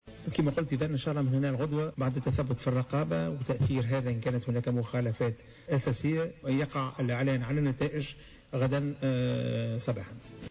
Le président de l’instance supérieure indépendante pour les élections (ISIE) Chafik Sarsar, a annoncé ce lundi 24 novembre 2014 sur les ondes de Jawhara FM que les résultats des élections présidentielles seront annoncés dans la matinée du 25 novembre.
Ecoutez sa déclaration Chafik Sarsar t√©l√©charger partager sur